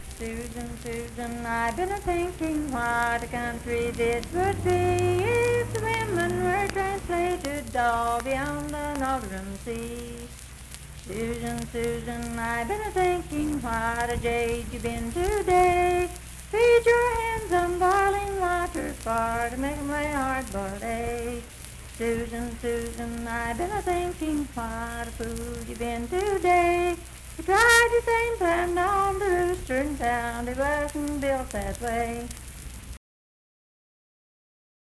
Unaccompanied vocal music
Verse-refrain 3(4).
Voice (sung)